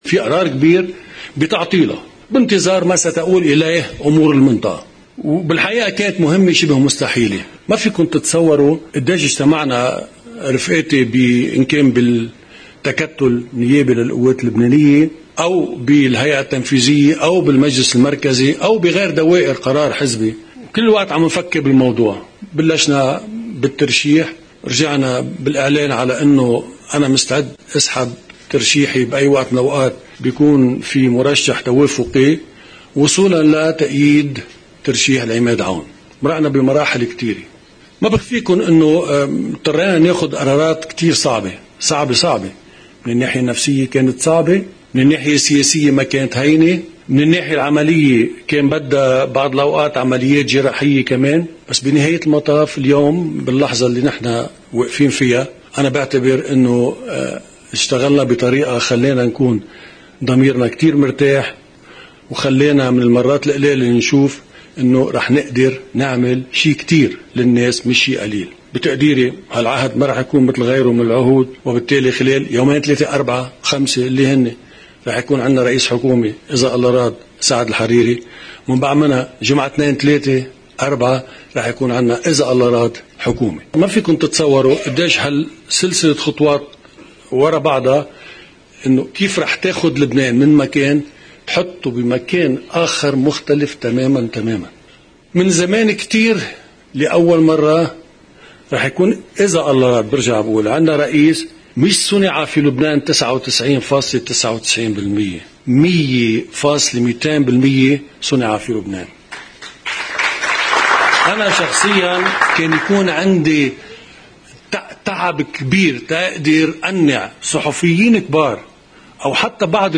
مقتطفات من كلمة لرئيس القوات سمير جعجع خلال مؤتمر “تعزيز الشفافية في قطاع النفط والغاز في لبنان”: